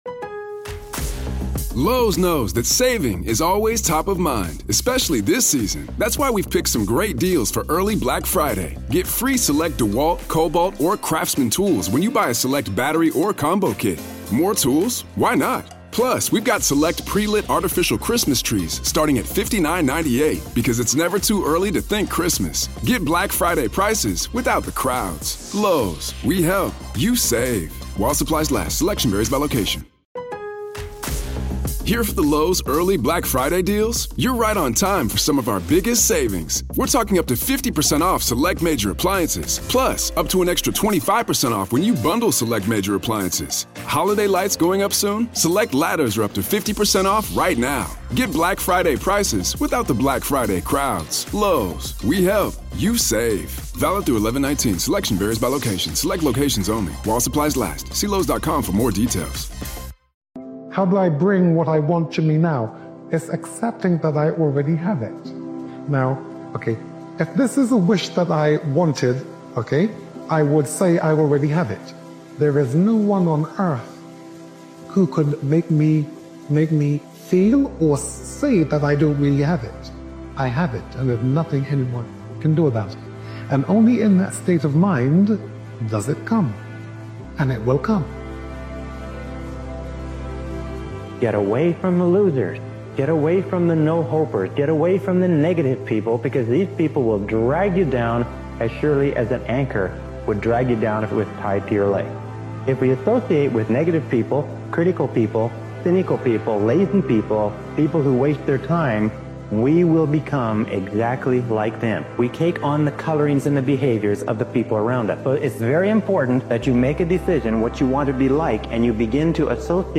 This focused motivational speeches compilation reminds you that emotions fade, but commitment builds results. Success doesn’t respond to moods—it responds to structure, focus, and follow-through.